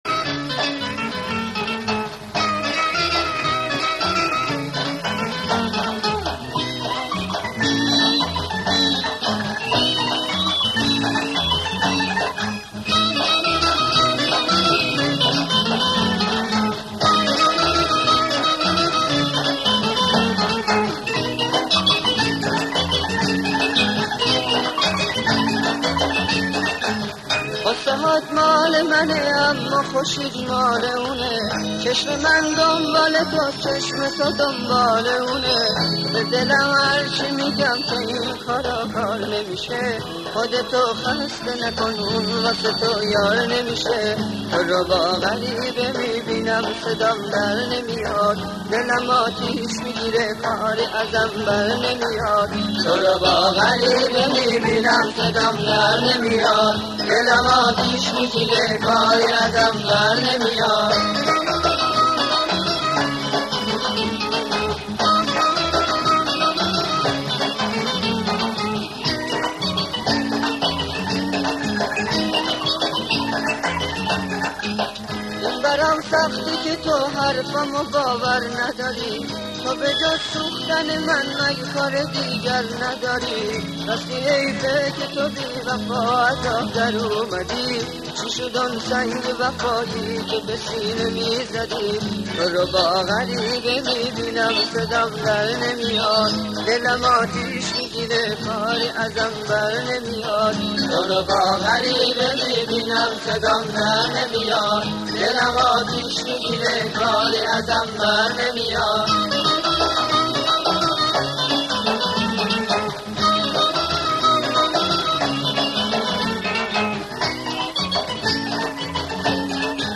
اهنگ شاد ایرانی
اهنگ شاد قدیمی